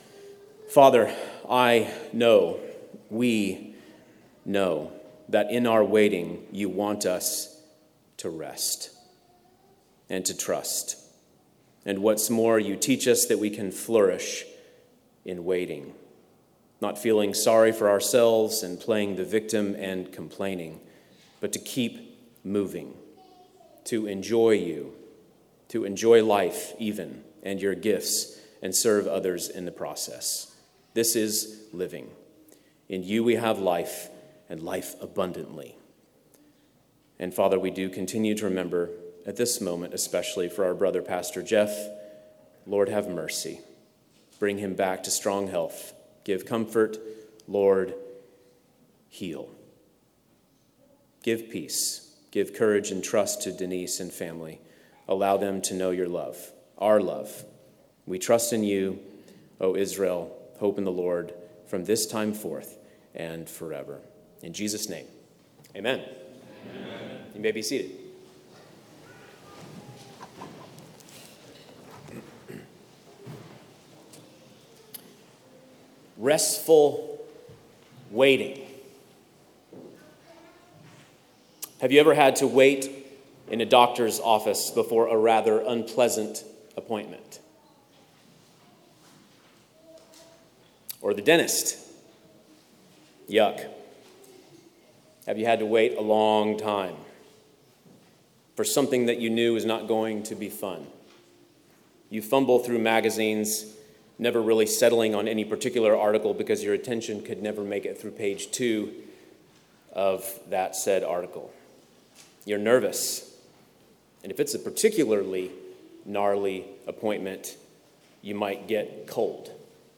The Psalms of Ascent Passage: Psalm 130 & Psalm 131 Service Type: Sunday worship « Psalm 145–In Praise of God the King